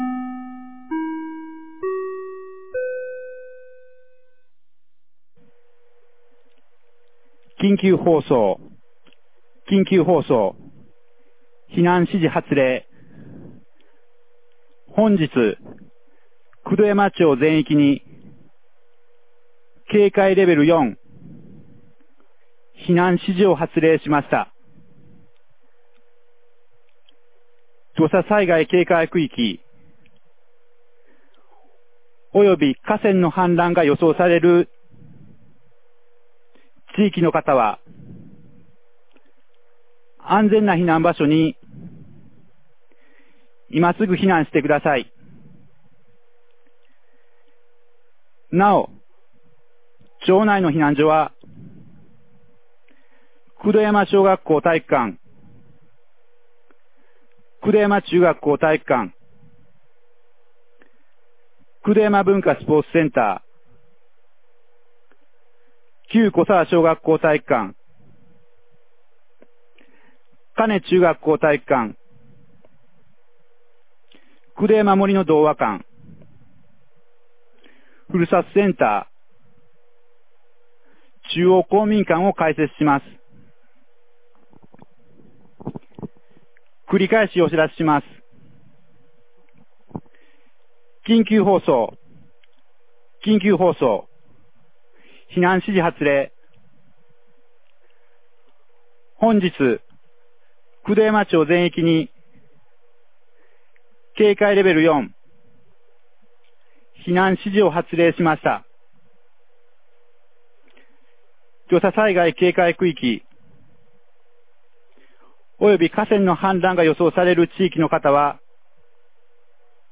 2023年06月02日 13時01分に、九度山町より全地区へ放送がありました。